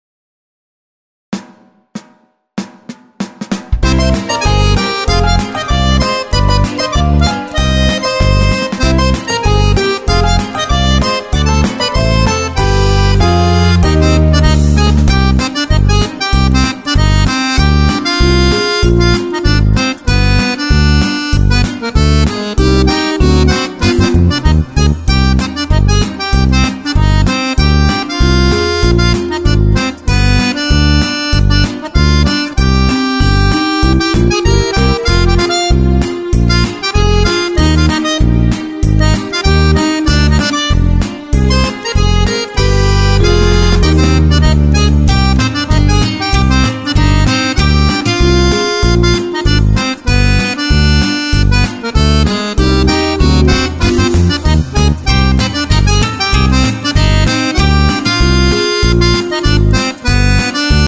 Playback - audio karaoke für Akkordeon